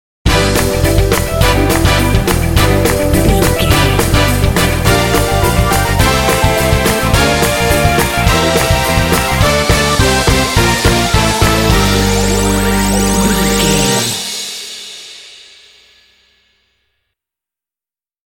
Fast paced
Aeolian/Minor
Fast
energetic
lively
dark
groovy
funky
synthesiser
drums
bass guitar
brass
electric guitar
synth-pop
new wave